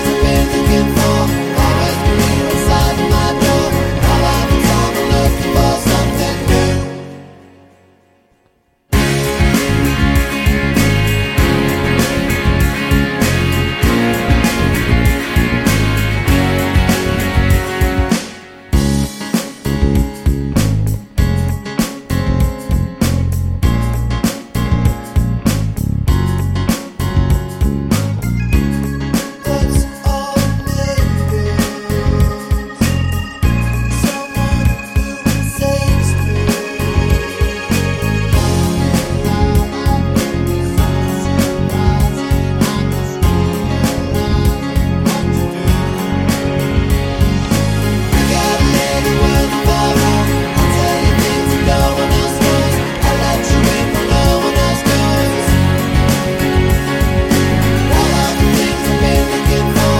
Twofers Medley Pop (2000s) 4:28 Buy £1.50